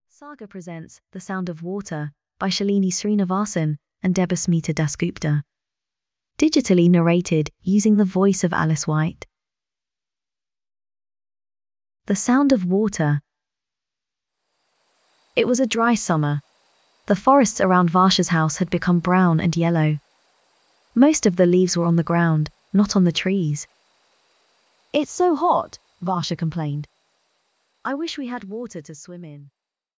The Sound of Water (EN) audiokniha
Ukázka z knihy